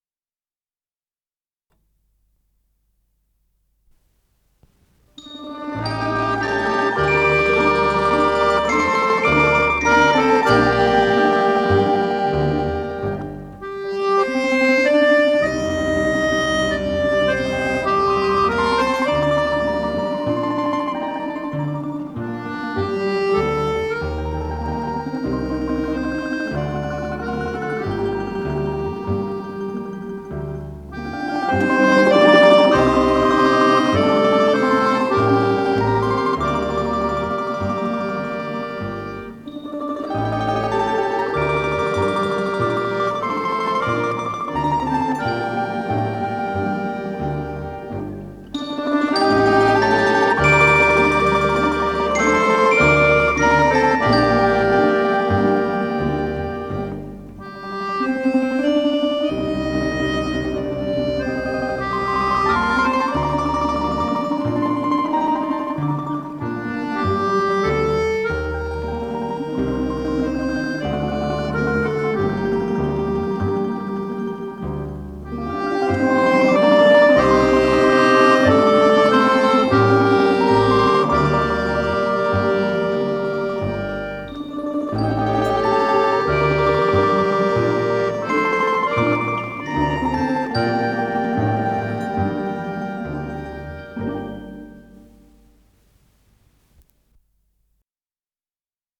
ИсполнителиАнсамбль народных инструментов
Скорость ленты38 см/с